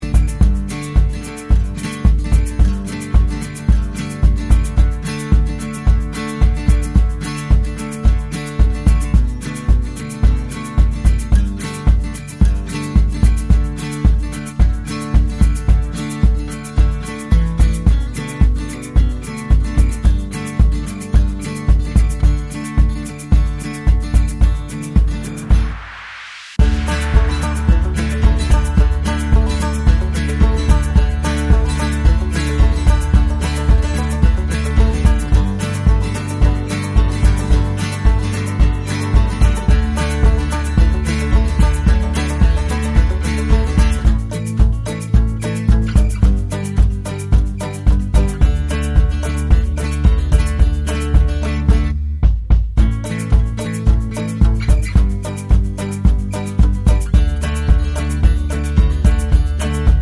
no Backing Vocals R'n'B / Hip Hop 3:55 Buy £1.50